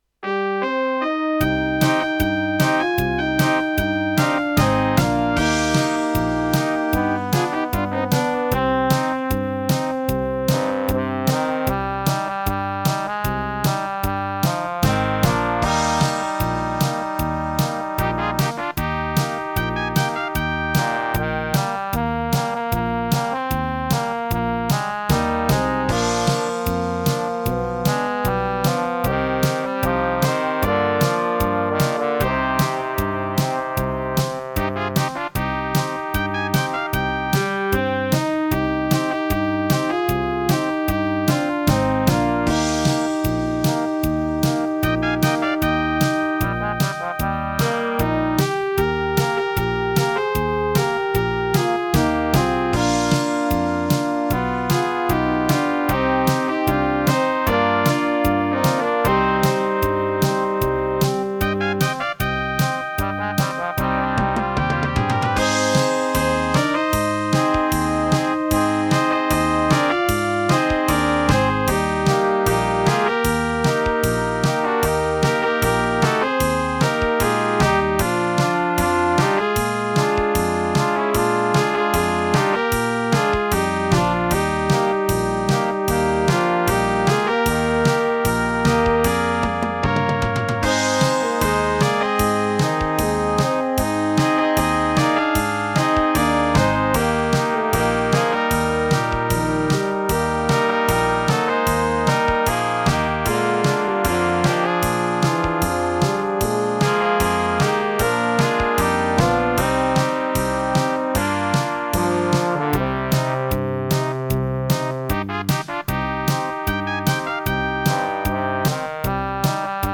Gattung: Für kleine Besetzung
Besetzung: Kleine Blasmusik-Besetzung
Schlagzeug (ad libitum)
Akkordeon/Gitarre (ad libitum)